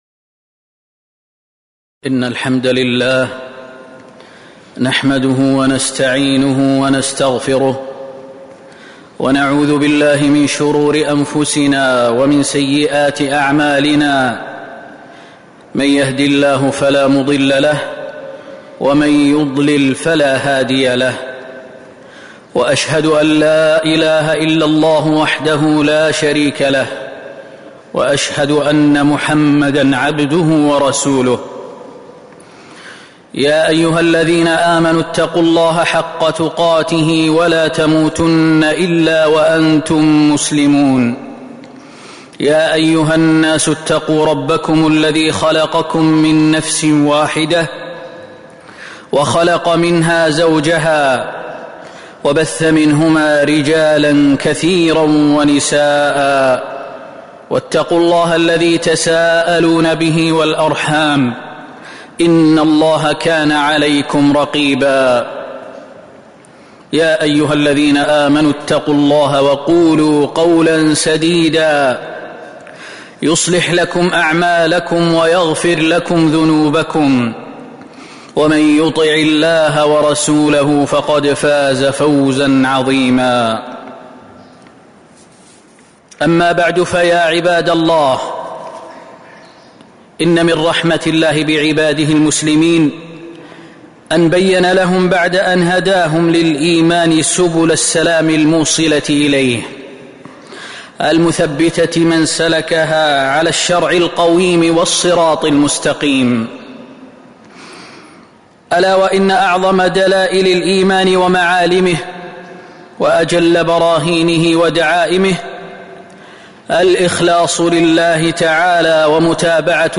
تاريخ النشر ١٦ محرم ١٤٤٧ هـ المكان: المسجد النبوي الشيخ: فضيلة الشيخ د. خالد بن سليمان المهنا فضيلة الشيخ د. خالد بن سليمان المهنا فضل الإخلاص والمتابعة